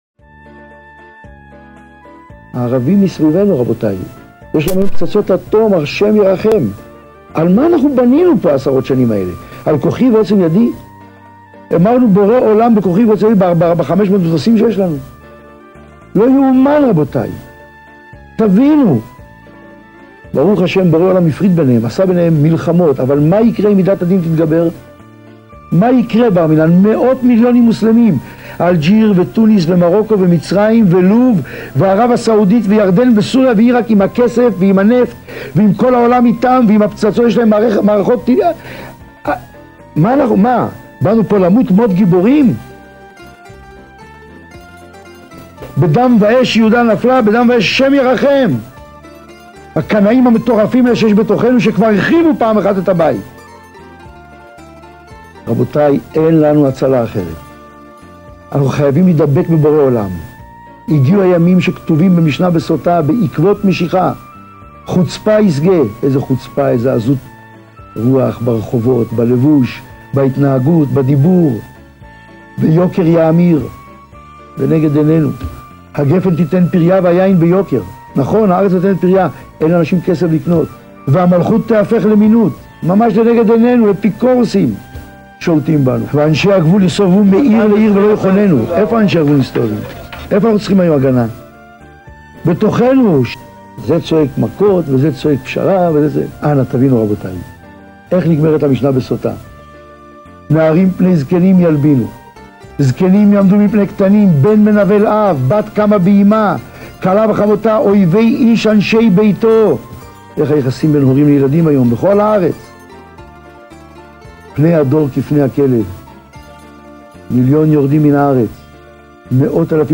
שעור תורה לזיכוי הרבים